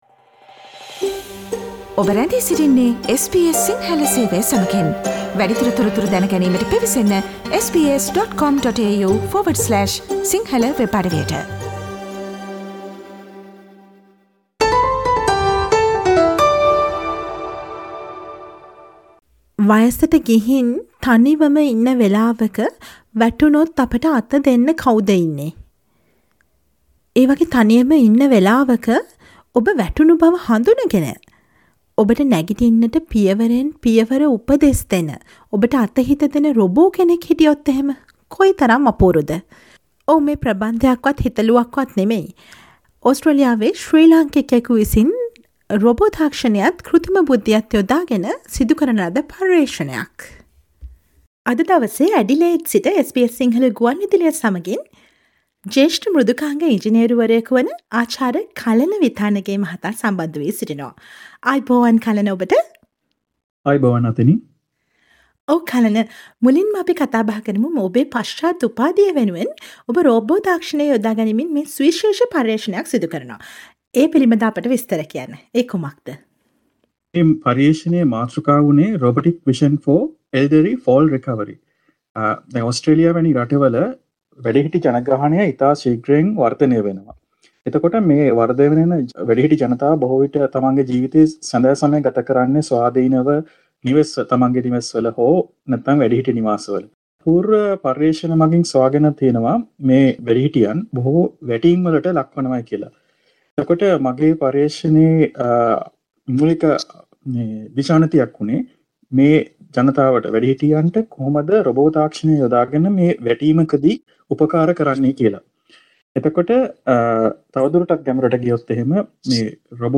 SBS සිංහල ගුවන් විදුලිය සමඟ සිදුකළ සාකච්ඡාව